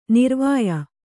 ♪ nirvāya